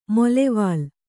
♪ molevāl